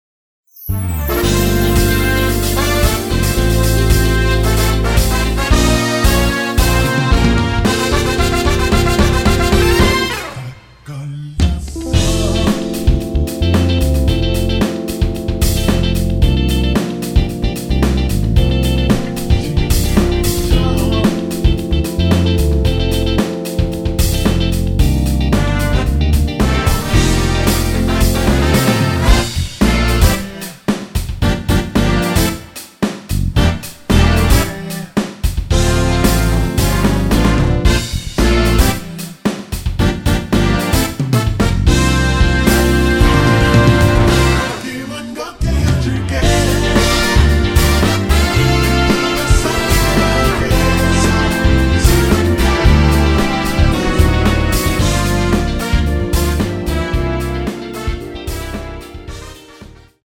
(-3) 내린 코러스 포함된 MR 입니다.(미리듣기 참조)
앞부분30초, 뒷부분30초씩 편집해서 올려 드리고 있습니다.
중간에 음이 끈어지고 다시 나오는 이유는